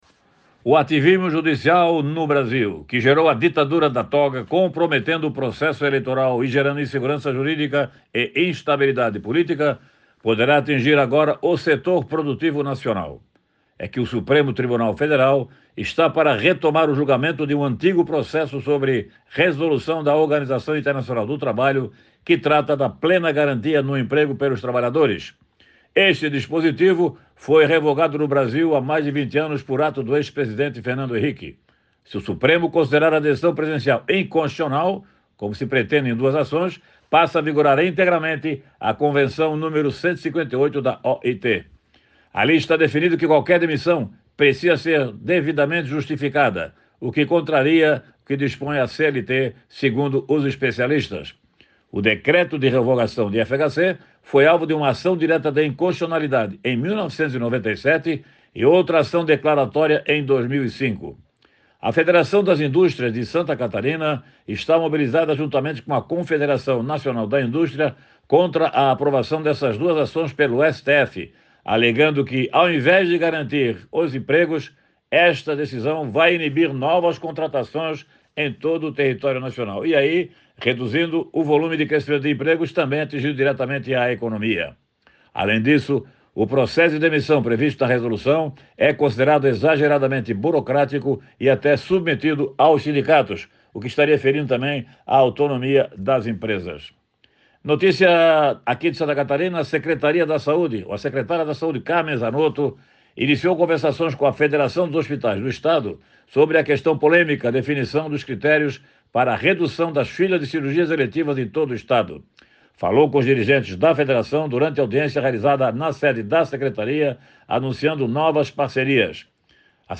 O jornalista comenta sobre atos que comprometem o processo eleitoral e que geram instabilidade política no país